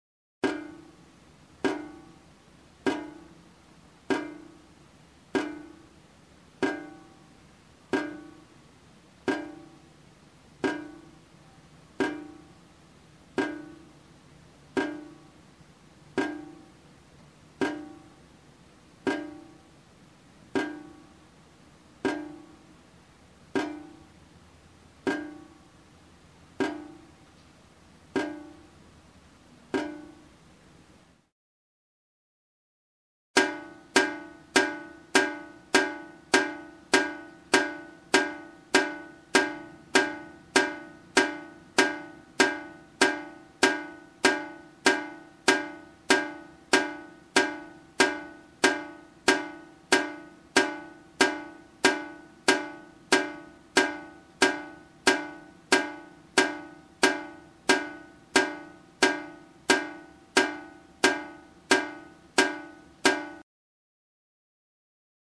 Звук капающей воды в металлическую раковину